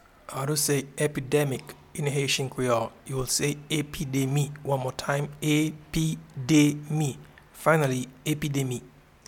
Pronunciation and Transcript:
Epidemic-in-Haitian-Creole-Epidemi.mp3